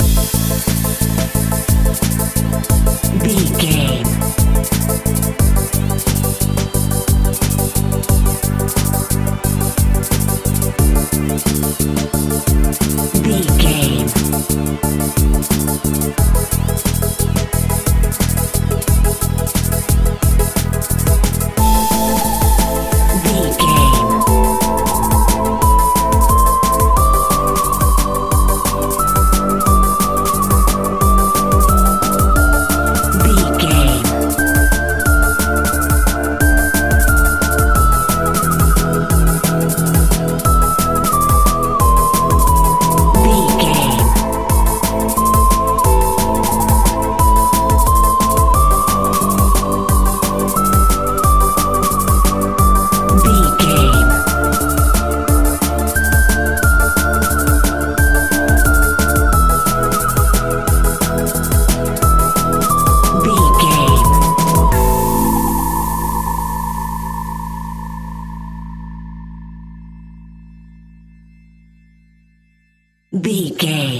Aeolian/Minor
Fast
groovy
uplifting
futuristic
driving
energetic
repetitive
drum machine
synthesiser
organ
electronic
sub bass
synth leads
synth bass